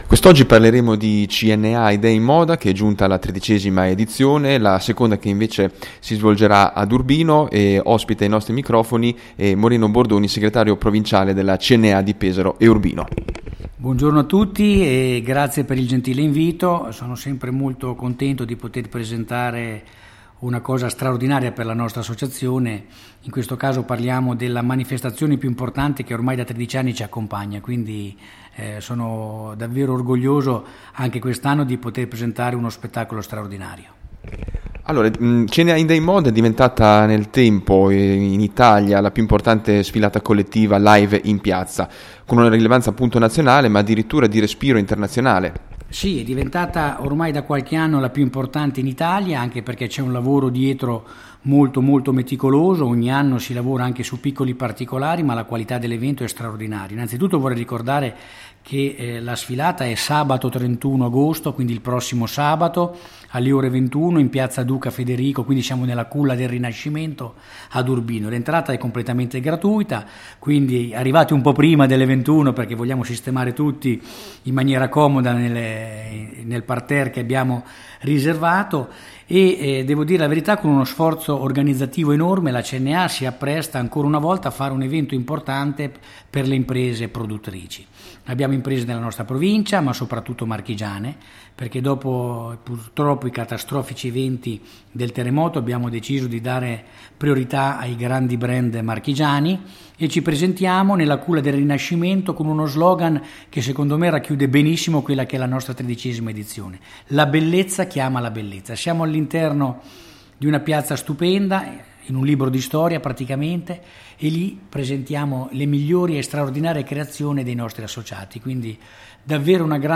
La nostra Intervista